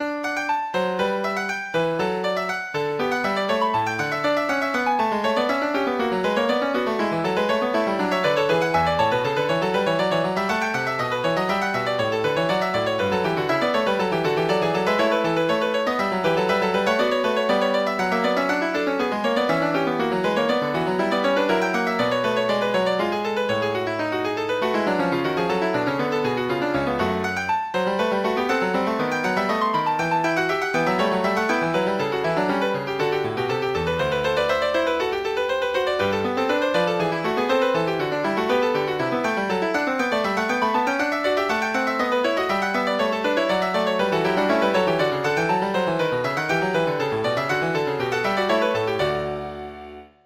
Piano version
Piano Classical